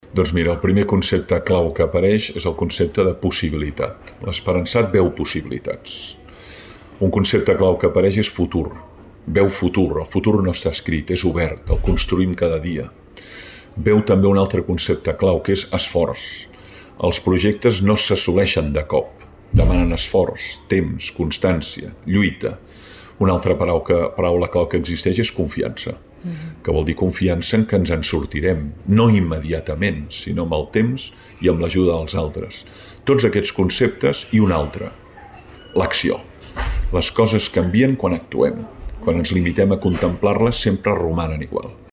La Biblioteca Francesc Pujols s’ha omplert de lectors i amants de la filosofia amb motiu de la presentació del llibre Anatomia de l’esperança, la nova obra del pensador Francesc Torralba.
Francesc Torralba, autor 'Anatomia de l'esperança'